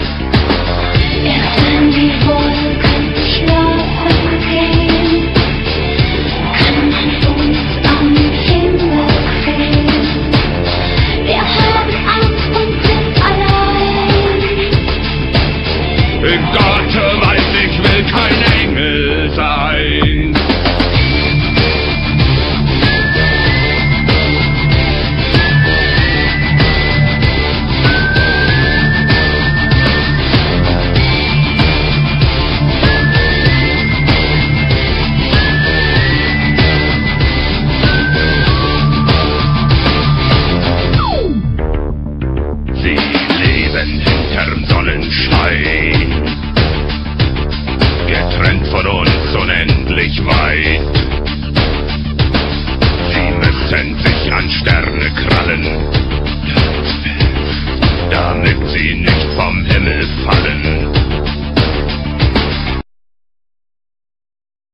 metal
they have a heavy, machine like rythem
and growling vocals.